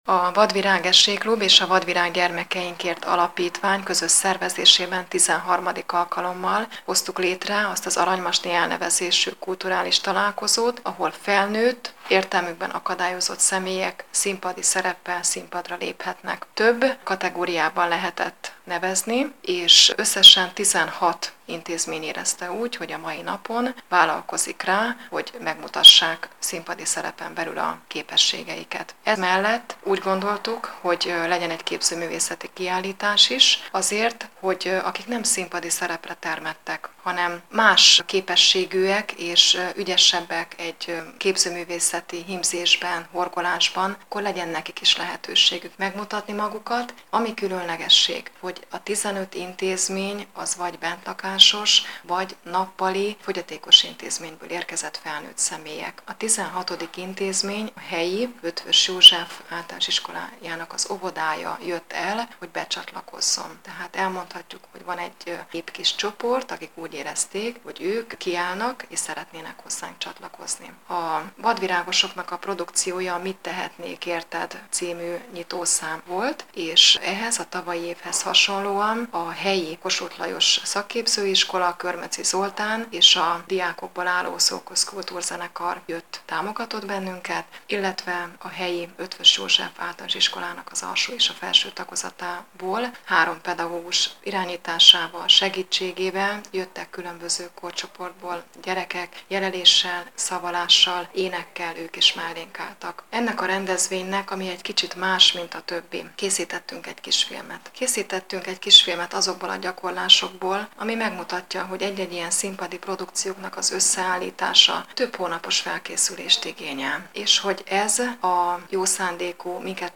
A Vadvirág Esély Klub és a Vadvirág Gyermekeinkért Alapítvány közös szervezésében került megrendezésre, immáron 13. alkalommal, az Aranymasni Kulturális Találkozó, ahol felnőtt, érrtelmükben akadályozott személyek léptek színpadra az orosházi Petőfi Művelődési Központban.